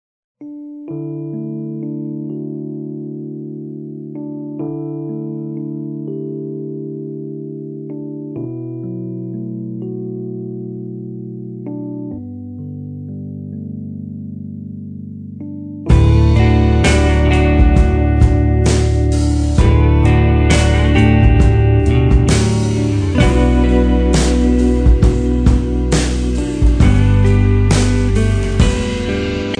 Post rock ed Ambient.